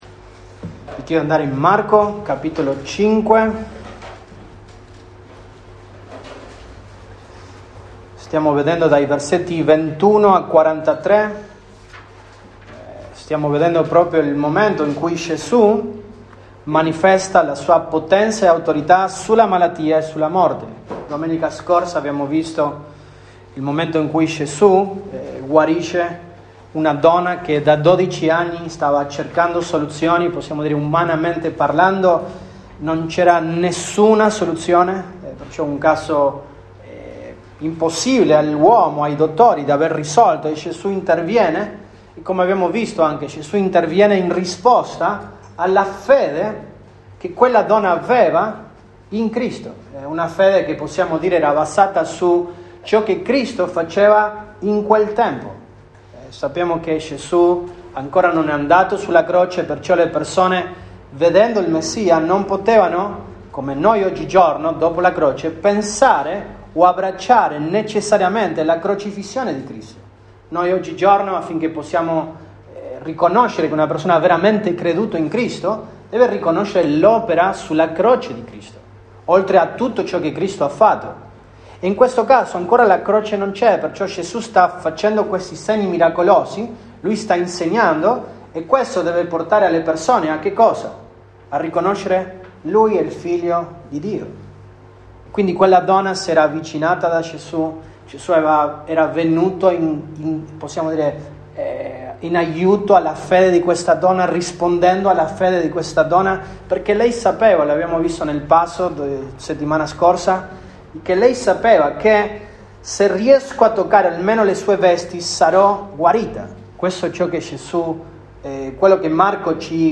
Set 24, 2023 L’autorità e la potenza di Gesù Cristo sulla malattia e la morte, 2° parte MP3 Note Sermoni in questa serie L’autorità e la potenza di Gesù Cristo sulla malattia e la morte, 2° parte.